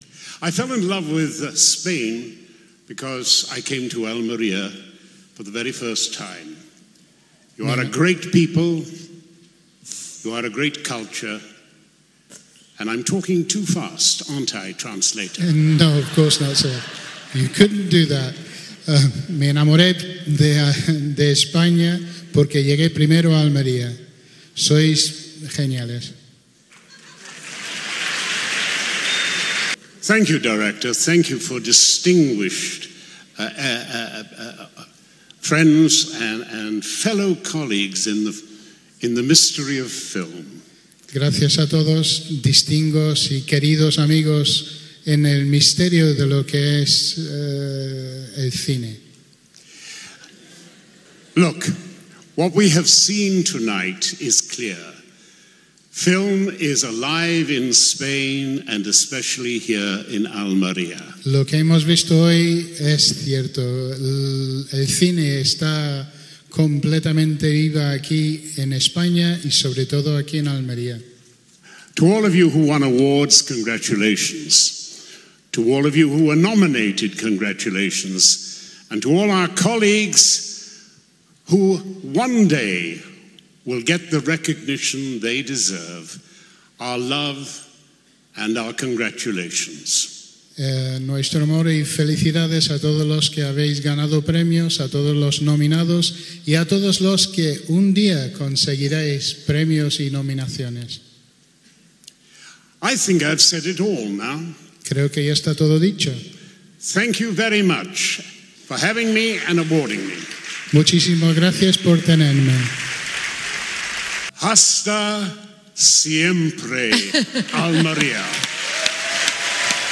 22-11_fical_gala_john_rhys-davies.mp3